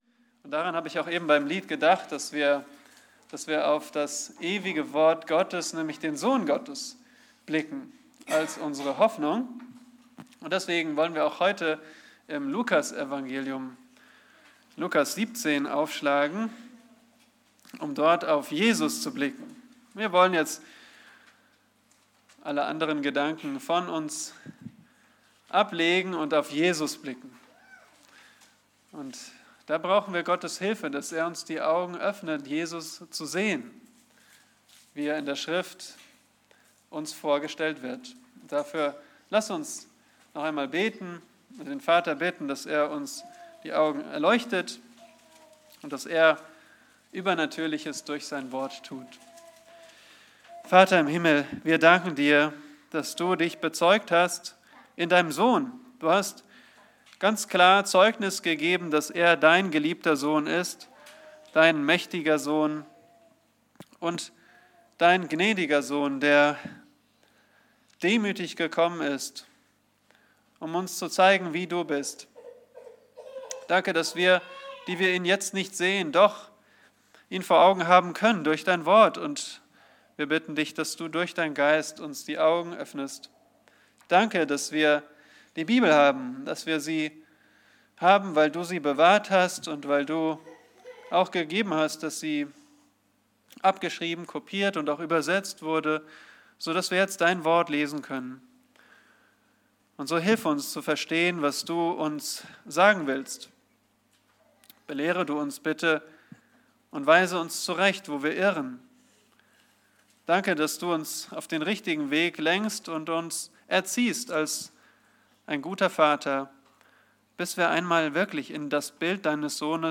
Predigten - Übersicht nach Serien - Bibelgemeinde Barnim